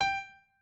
pianoadrib1_26.ogg